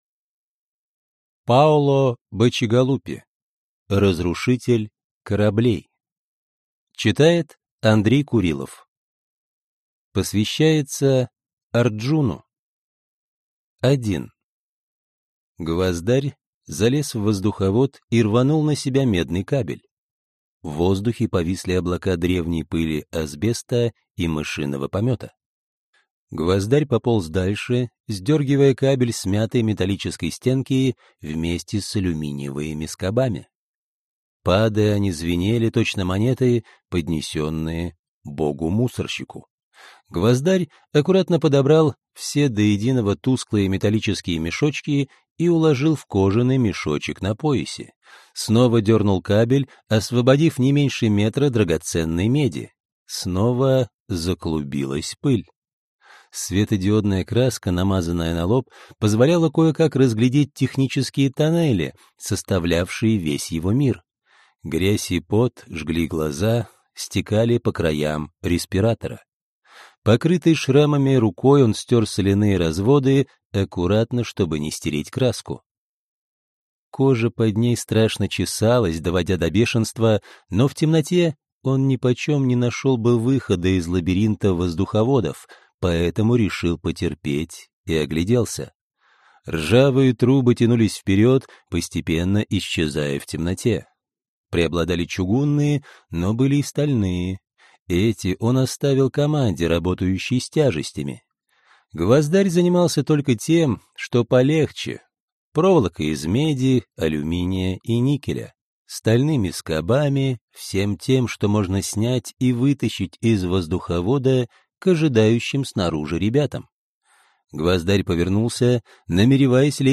Разрушитель кораблей (слушать аудиокнигу бесплатно) - автор Паоло Бачигалупи